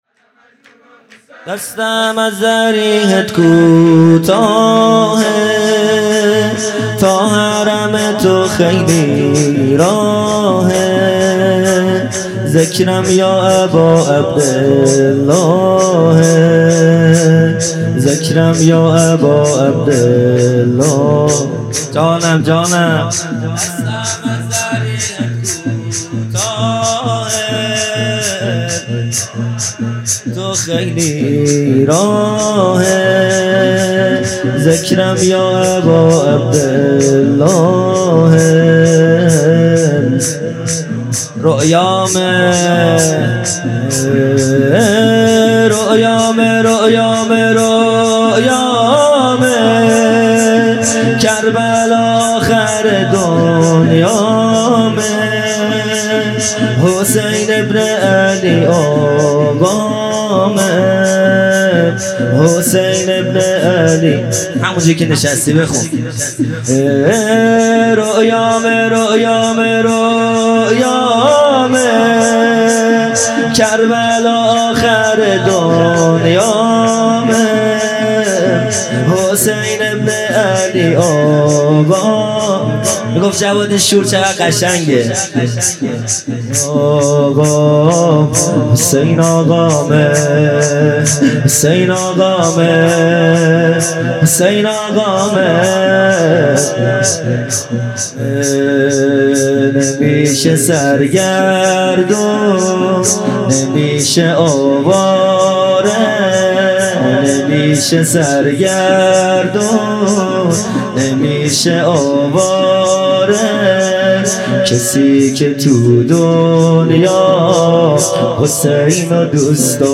خیمه گاه - هیئت بچه های فاطمه (س) - شور | دستم از ضریحت کوتاهه
عزاداری فاطمیه اول | شب اول